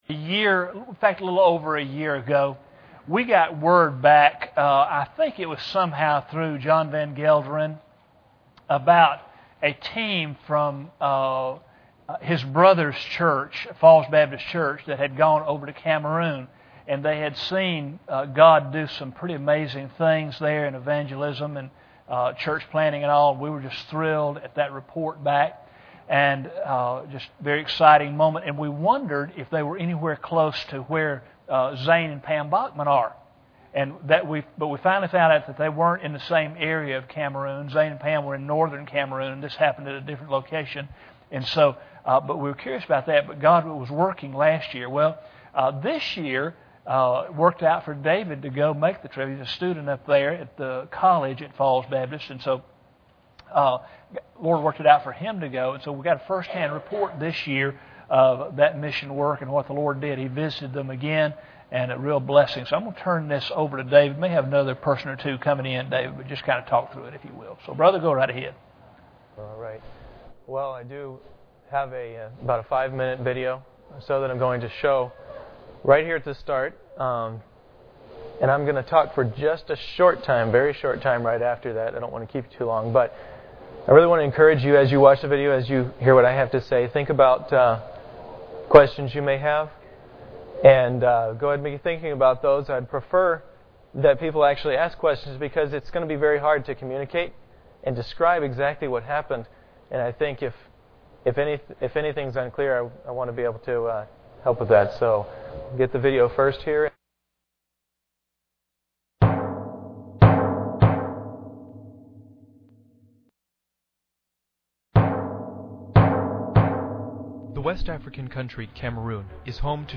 General Service Type: Sunday Evening Preacher